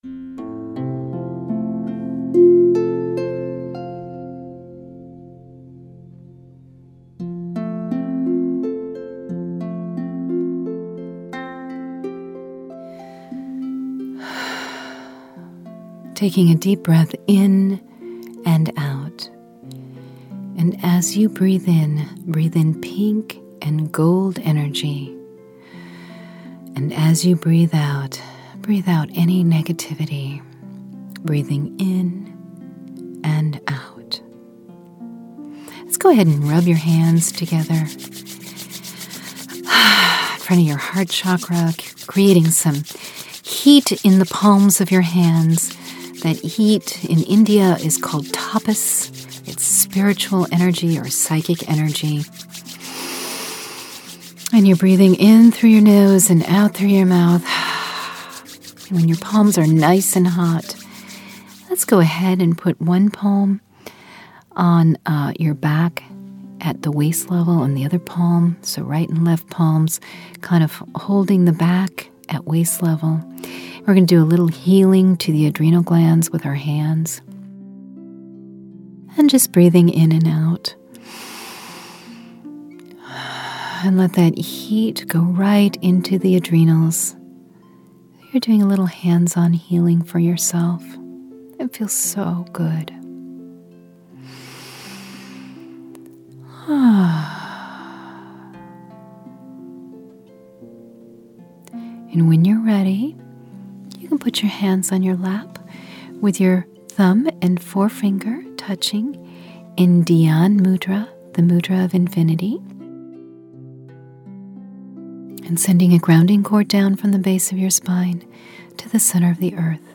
From+Fatigued+to+Fabulous+-+Guided+Imagery+Meditation.mp3